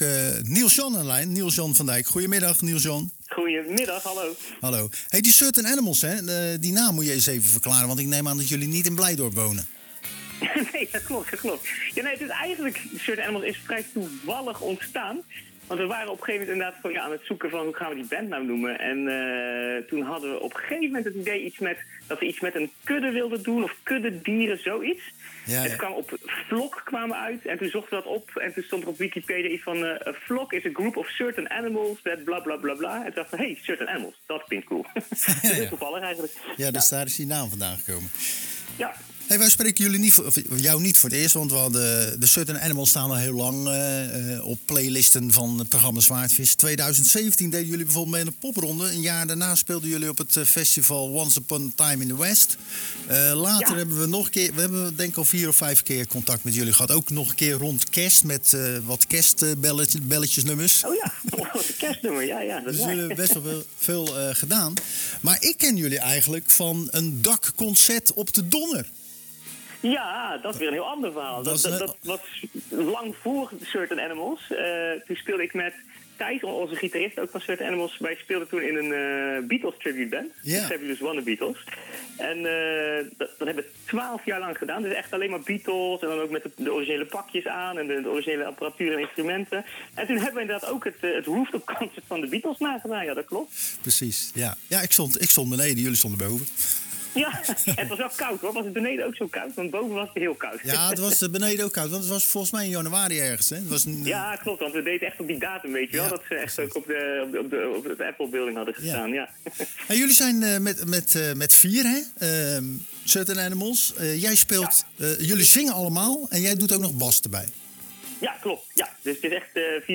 Tijdens de wekelijkse editie van Zwaardvis belden we een oude bekende van het programma.